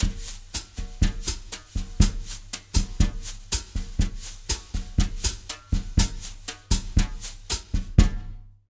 120BOSSA01-L.wav